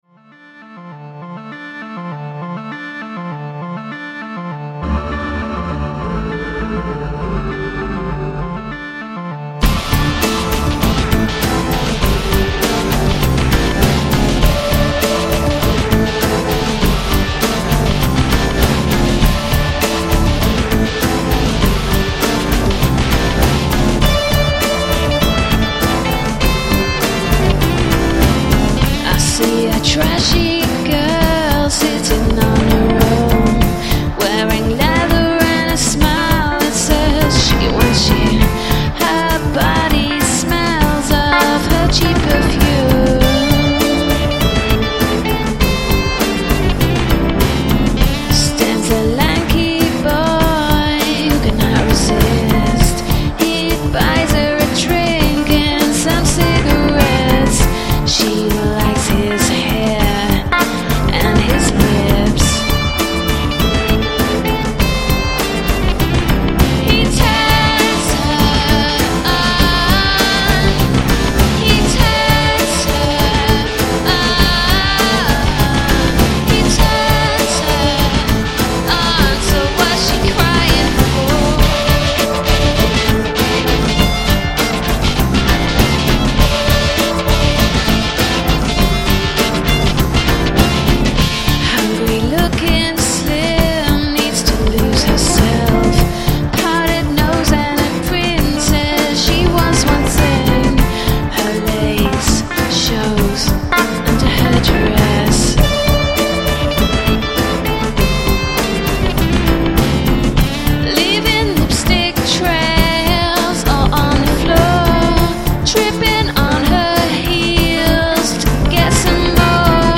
It's pretty rough around the edges, but it's a lot better than I remember.